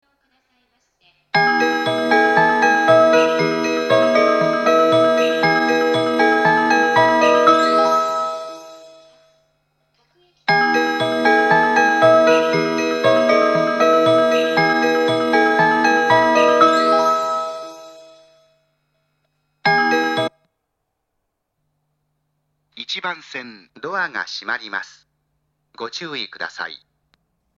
１番線発車メロディー 曲は「twilight」です。